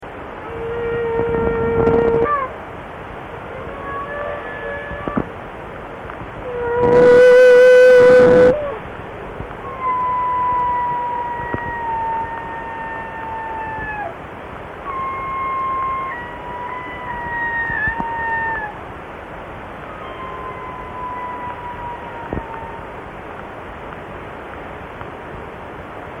Whale Song Archive - Alaska — Jupiter Research Foundation